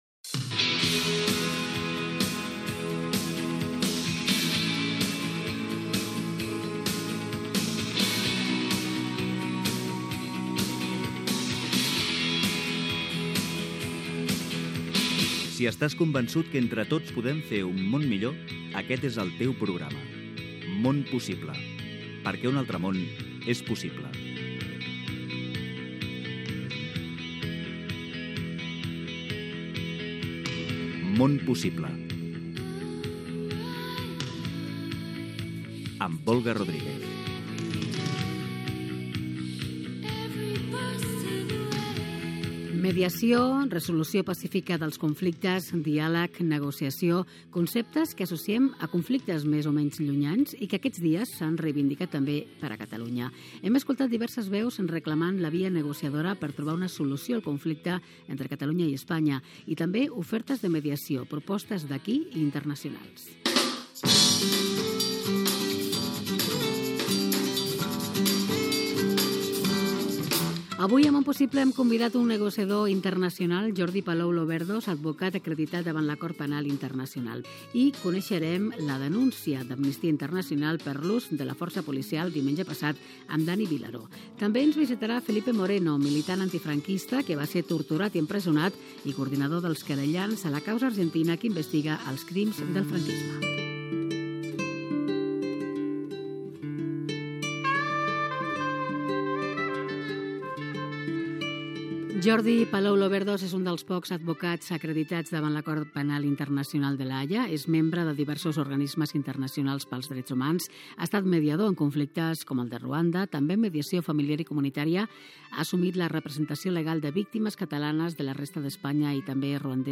Careta del programa, sumari de continguts, entrevista